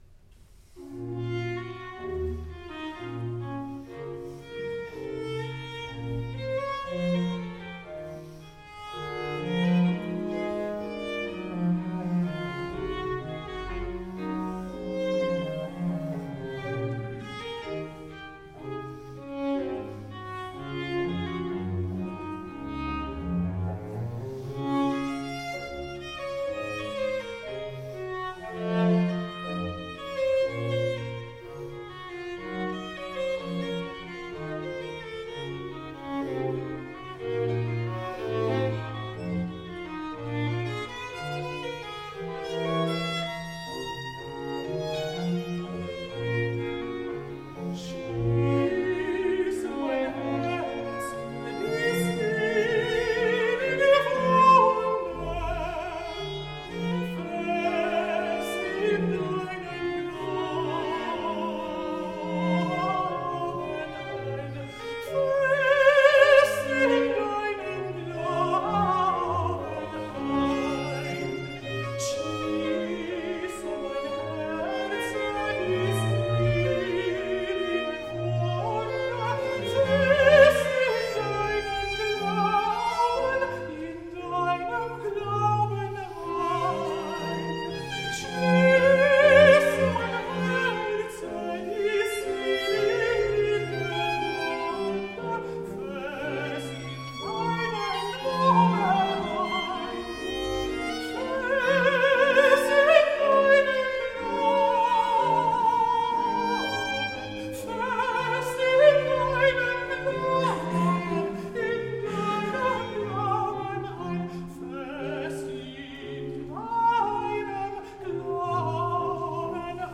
countertenor